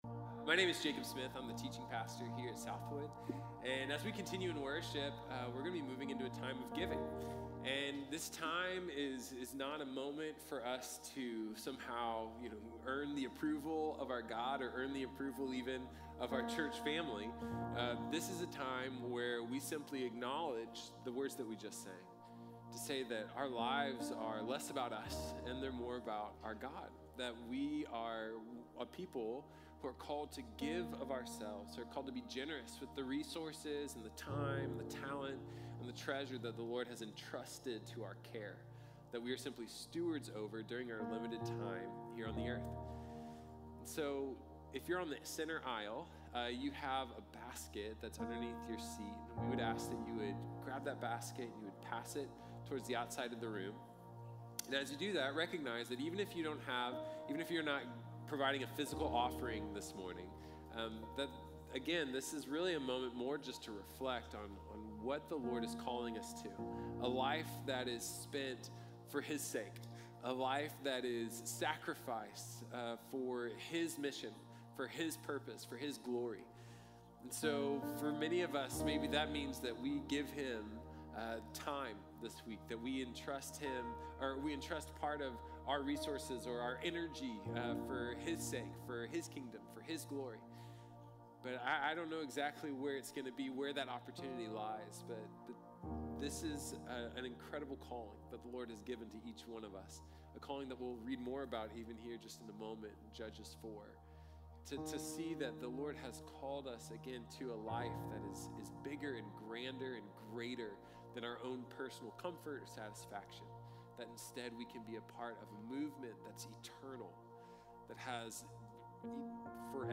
Deborah & Barak | Sermon | Grace Bible Church